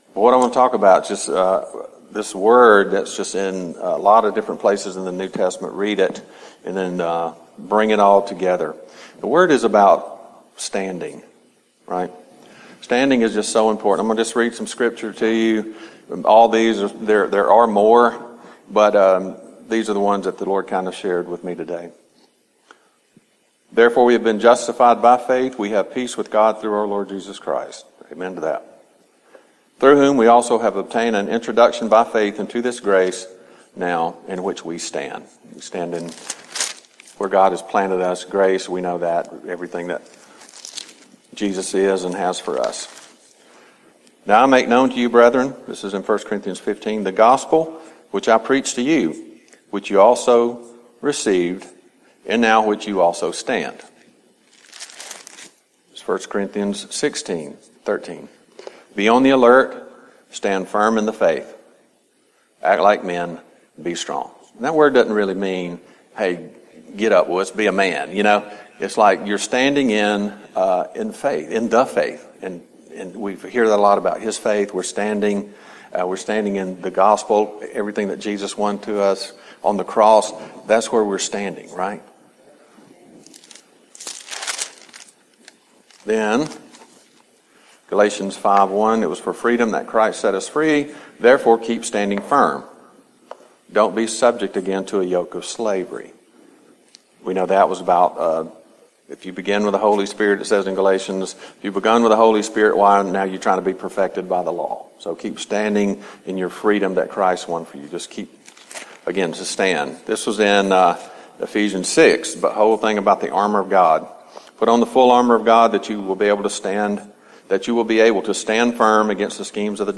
Service Type: CTK Noon Service